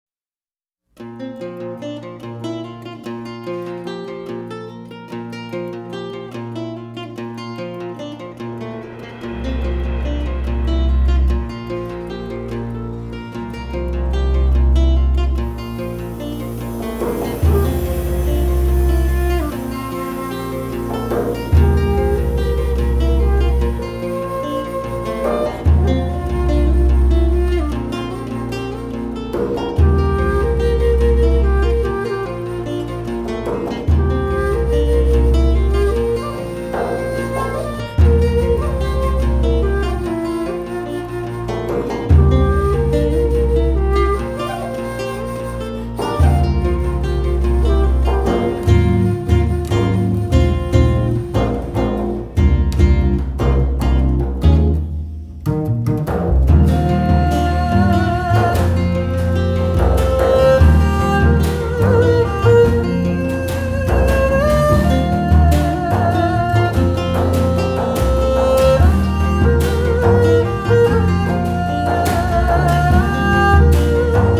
發燒演奏、發燒天碟
以演奏中國音樂混合世界音樂讓人耳目一新